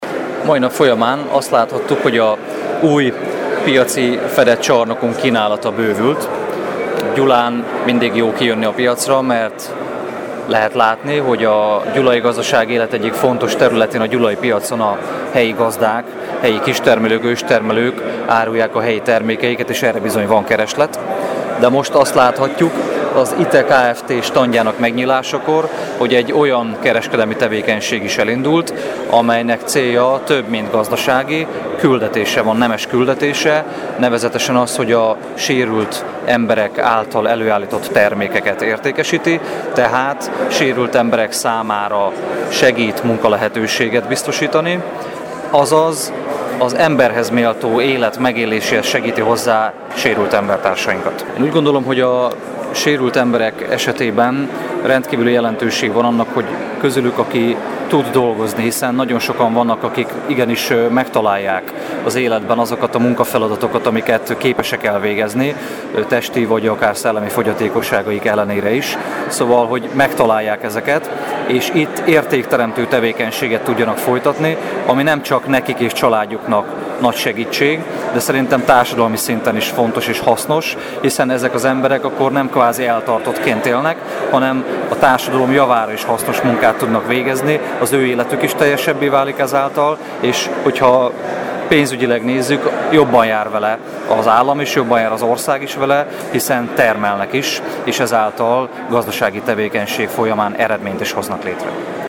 Pénteken nyitjotta meg a Gyulai Piac új vásárcsarnokában található árusító standját az Integration Through Employment Hungary Kft. Az eseménnyel kapcsolatban dr. Görgényi Ernőt Gyula város polgármestere nyilatkozott. A vágatlan riportot hallgathatja meg itt.